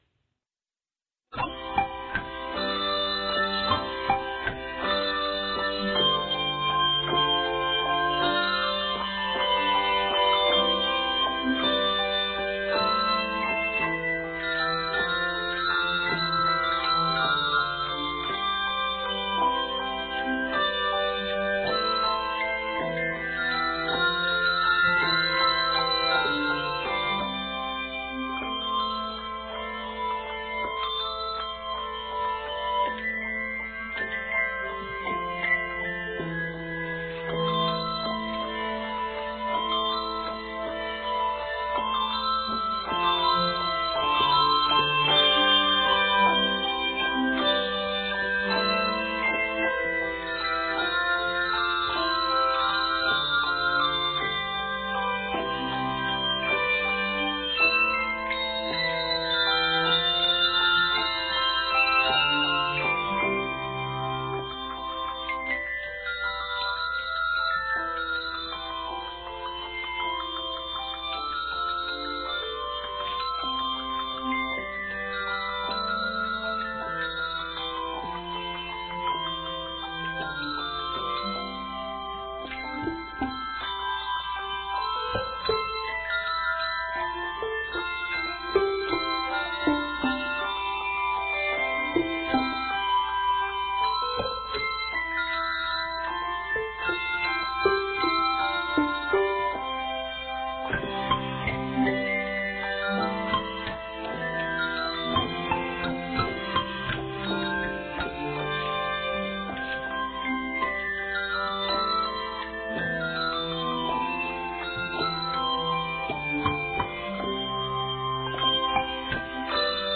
Octaves: 3-5 Level